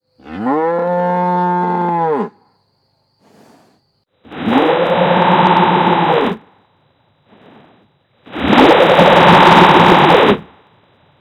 Implemented a small vocoder-like thing. It's very rough and I need 300+ oscilators to make vowels sound ok but it was fun to do.
Attached is a cow, a vocoded cow and a vovocoded cow.
vocowder.ogg